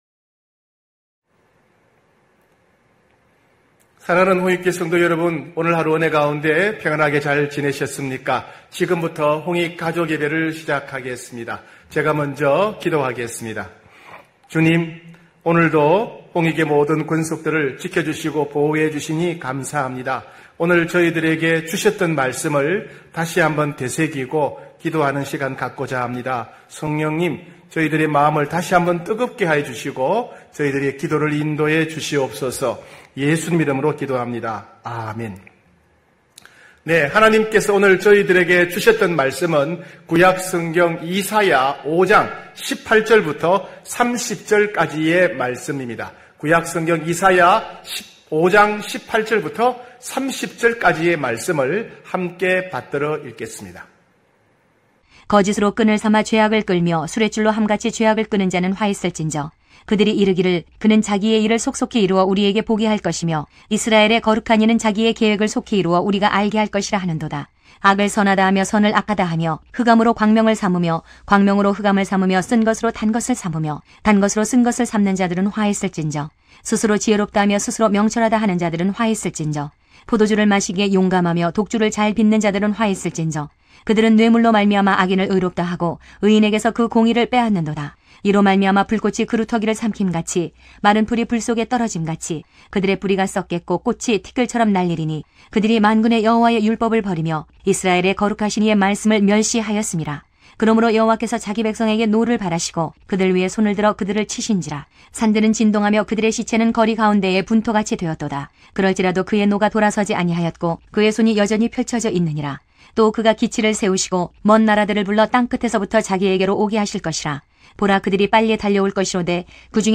9시홍익가족예배(7월16일).mp3